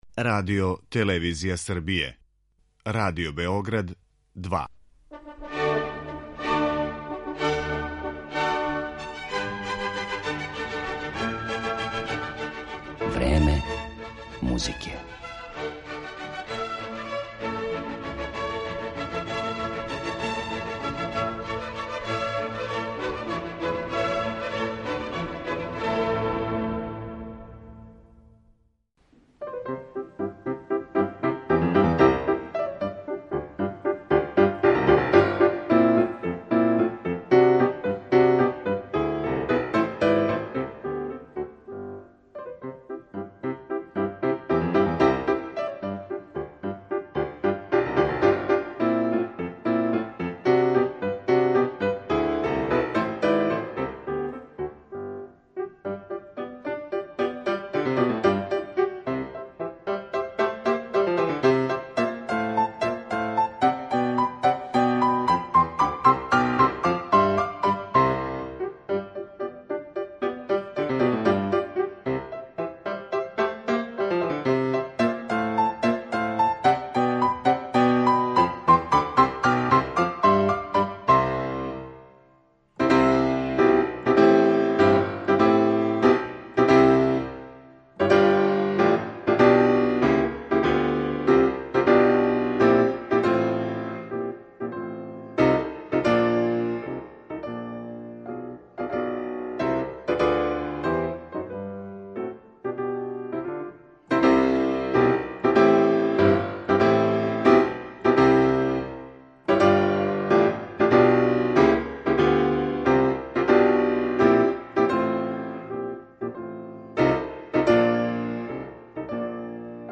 Разговор употпуњују снимци композиција Едварда Грига, Фредерика Шопена, Мориса Равела, Рахмањинова и (наравно) Бетовена.